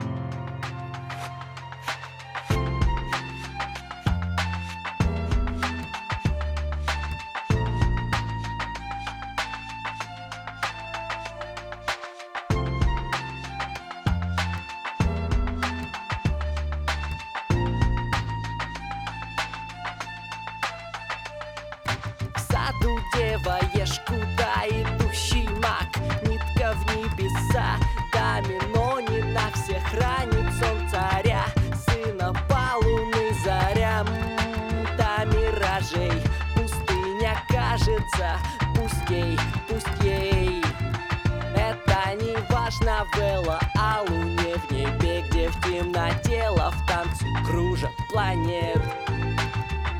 Маленький кусочек, сведение без мастеринга.